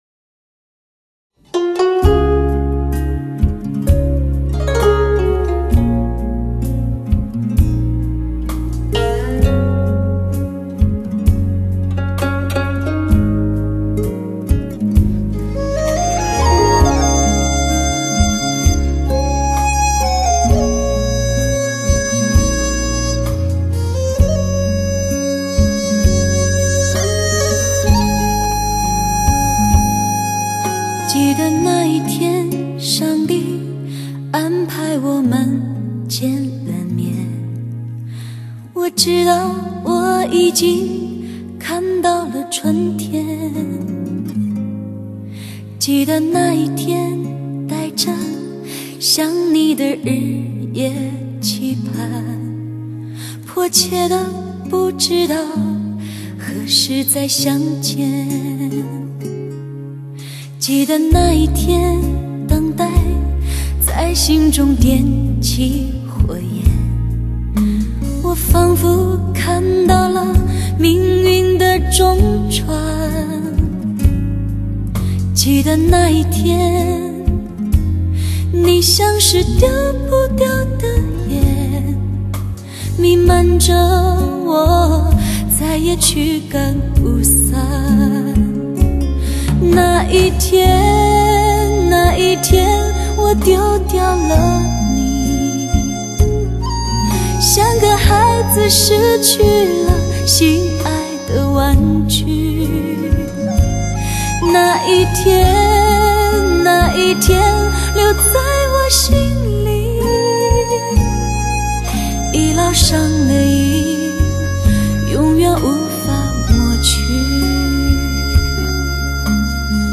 （试听为低品质wma格式，下载为320k/mp3）